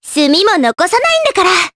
Cleo-Vox_Skill4_jp_b.wav